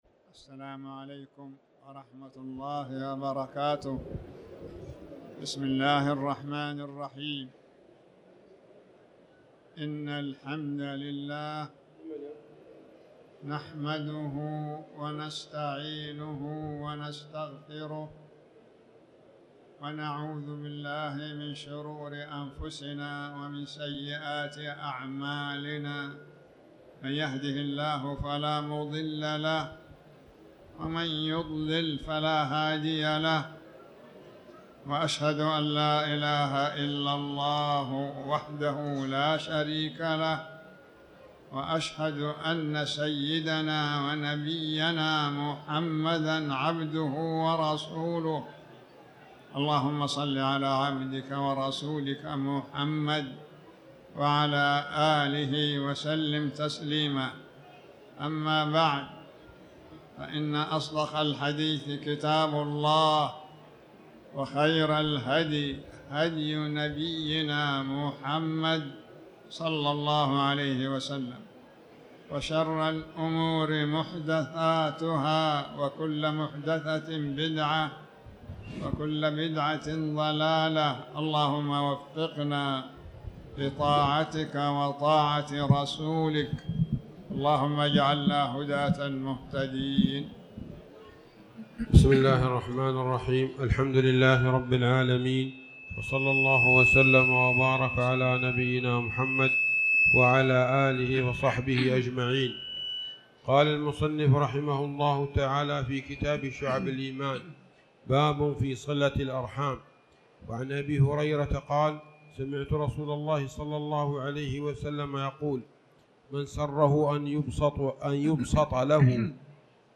تاريخ النشر ٧ جمادى الأولى ١٤٤٠ هـ المكان: المسجد الحرام الشيخ